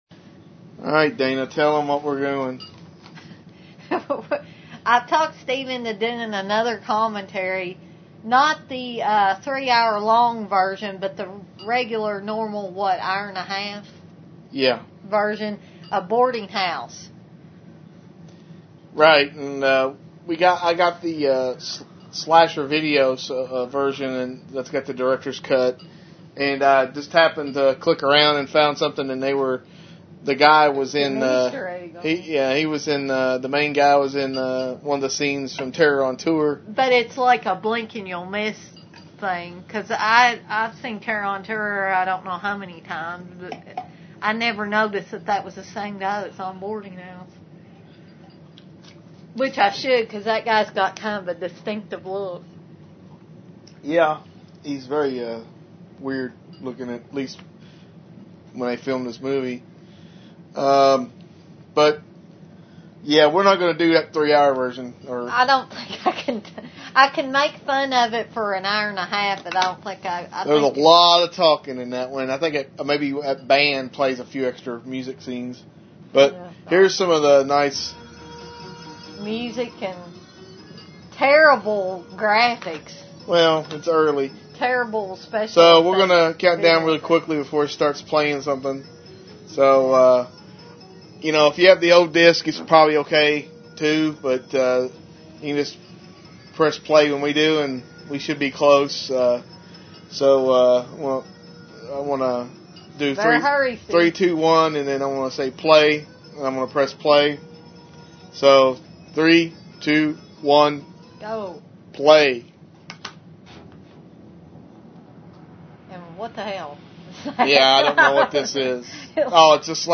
Fan Commentary